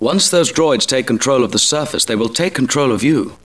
Listen to the sounds of Star Wars Episode one, with downloaded wav files for your listening pleasure.
--Obi-Wan Kenobi--